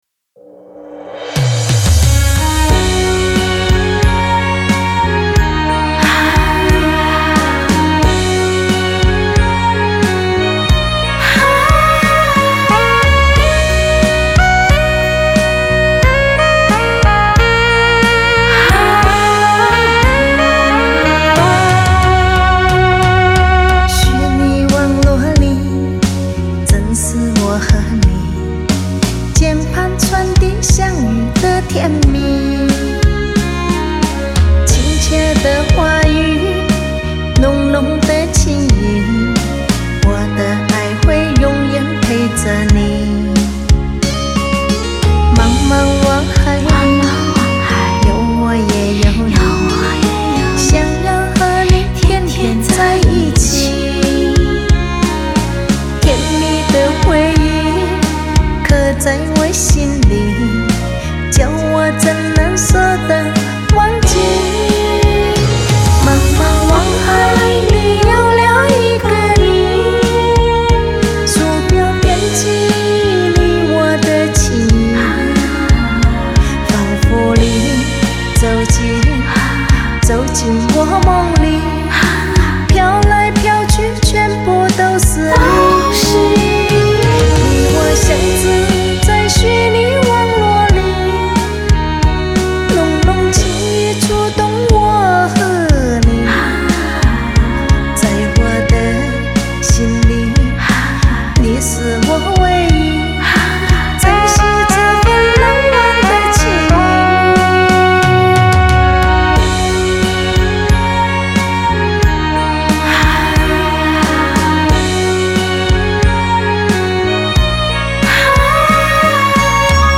音色柔和甜美有磁性的嗓子